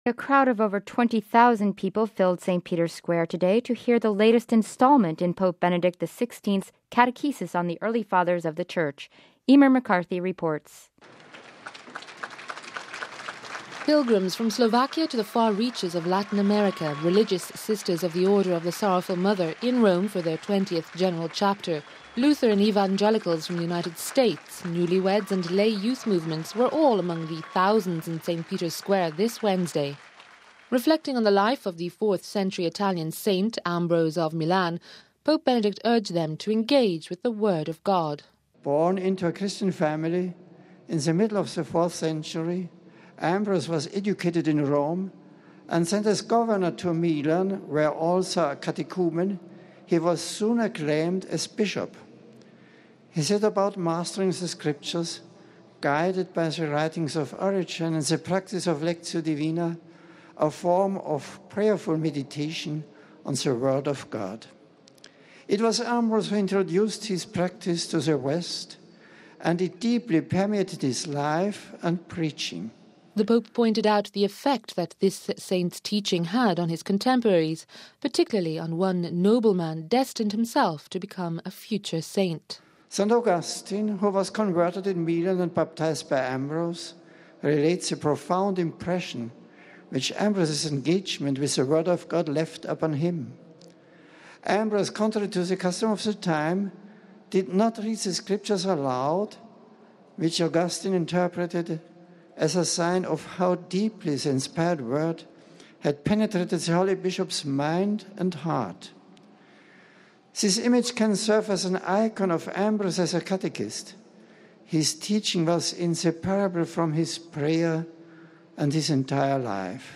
(24 Oct. 07 – RV) More than 20,000 people gathered in St. Peter Square to hear Pope Benedict XVI’s latest instalment of his catechesis on the early Church fathers.